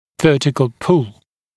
[‘vɜːtɪkl pul][‘вё:тикл пул]вертикальная тяга, тяга с вертикальным компонентом